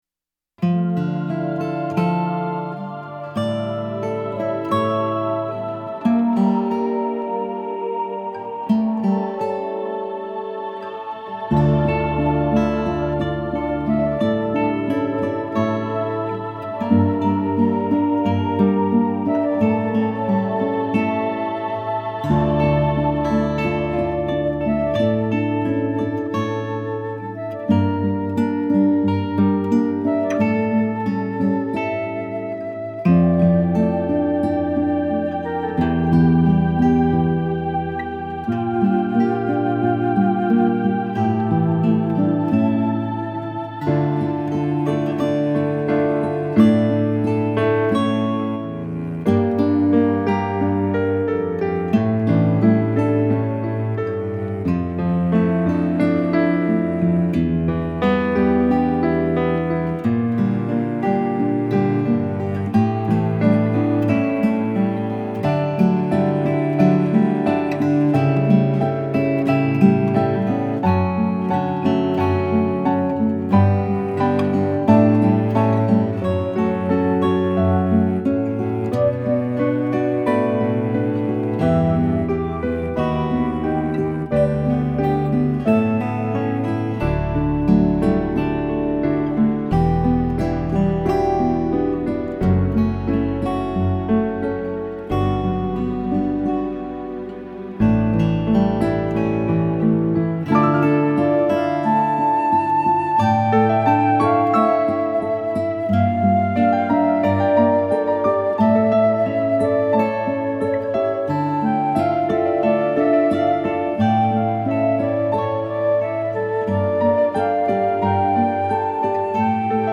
MY SHINING STAR – KARAOKE & GUITAR INSTRUMENTAL
It was very much like a classical guitar instrumental with dark minor chords.
Then while I was in Yosemite I wrote the chorus, which was in D major.
shining-star-guitar-mix1.mp3